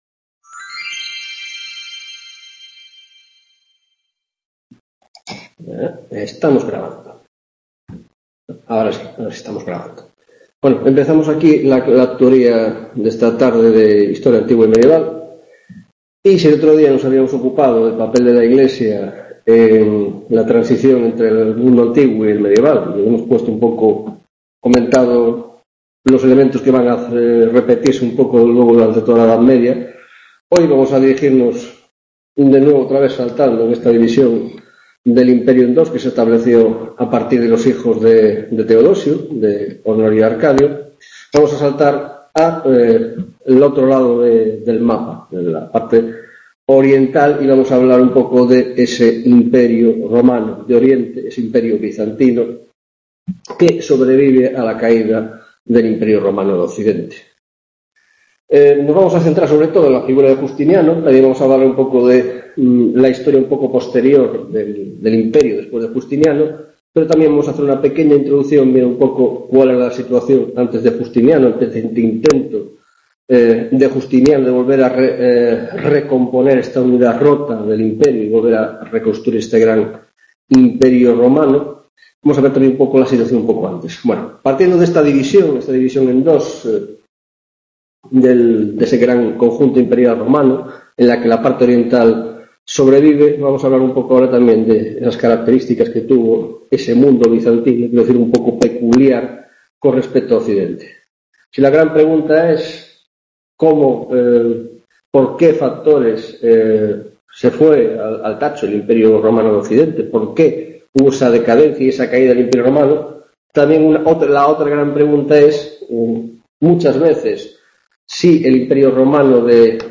10 ª Tutoria de Historia Antigua y Medieval, grado en Filosofia: Imperio Bizantino y la Era de Justiniano